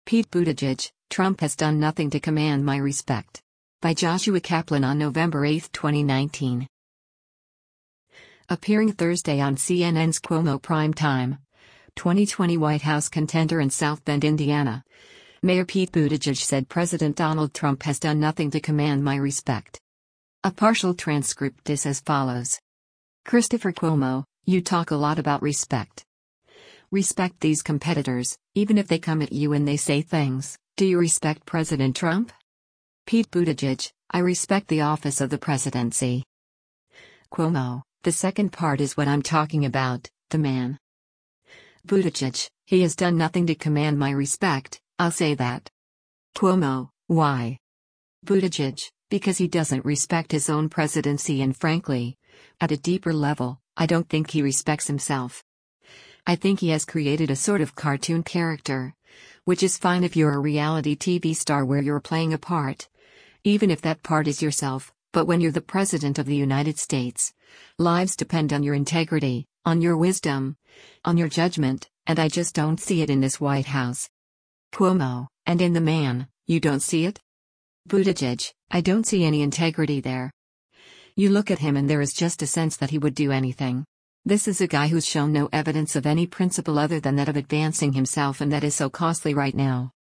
Appearing Thursday on CNN’s Cuomo Prime Time, 2020 White House contender and South Bend, Indiana, Mayor Pete Buttigieg said President Donald Trump has “done nothing to command my respect.”